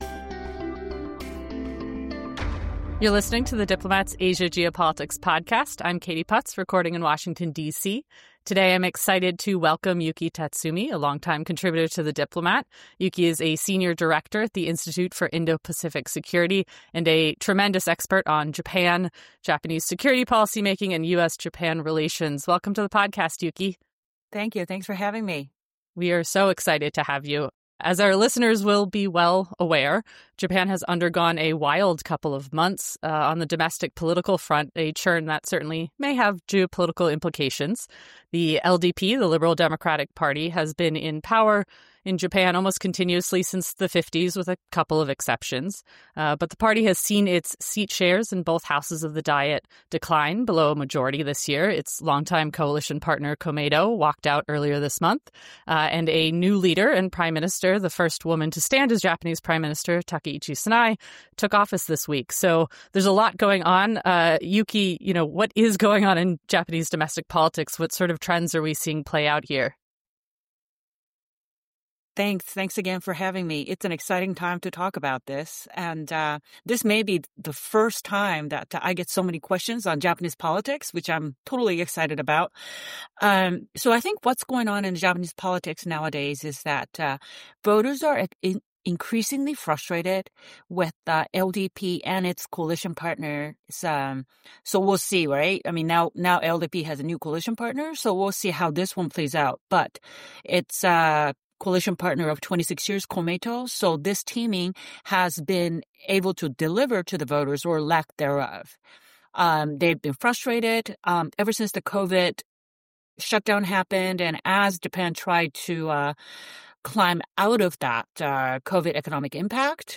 News Talk